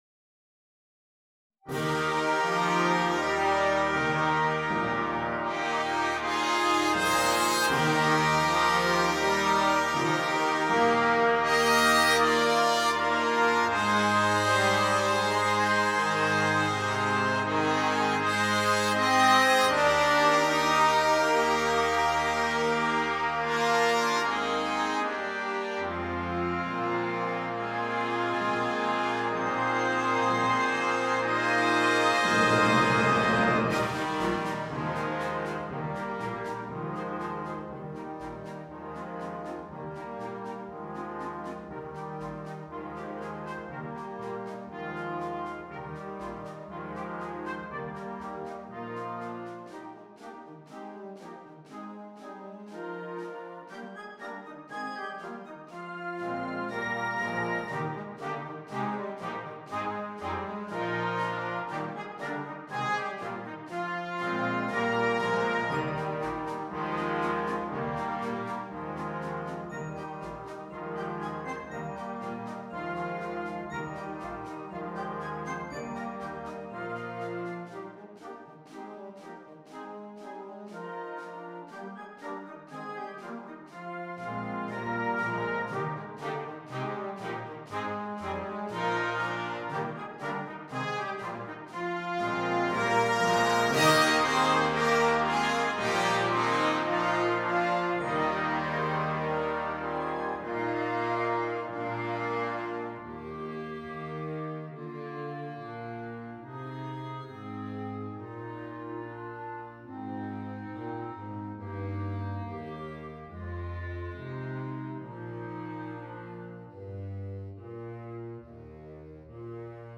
for Wind Band
An elegant composition, ideal for inclusion in any concert.
2+ Instrumentation: Wind Band £ 35.00 An elegant composition